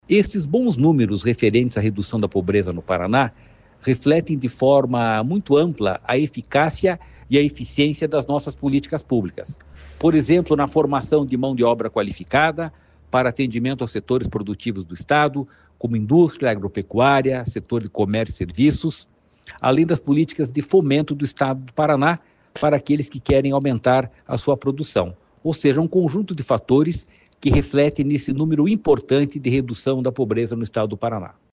Sonora do diretor-presidente do Ipardes, Jorge Callado, sobre a redução da pobreza no Paraná